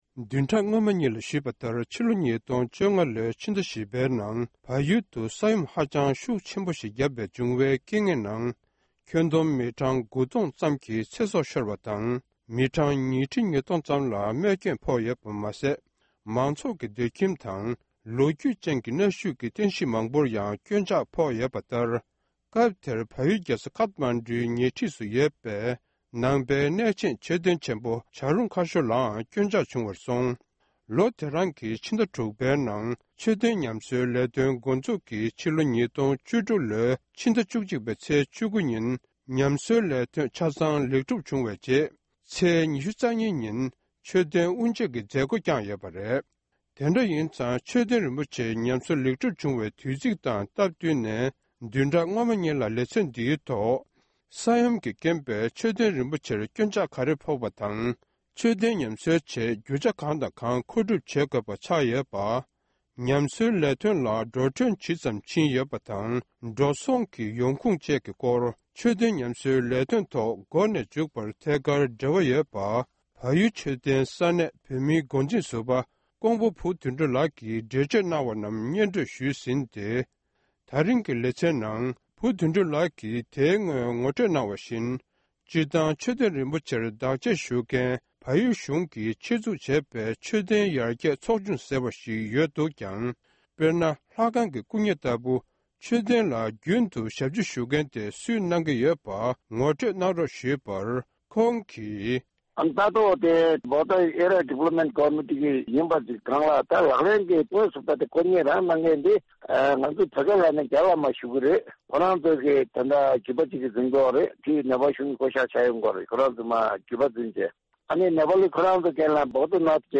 མཆོད་རྟེན་བྱ་རུང་ཁ་ཤོར་གྱི་ཉམས་གསོའི་ལས་དོན། ལེ་ཚན་གསུམ་པ། སྒྲ་ལྡན་གསར་འགྱུར།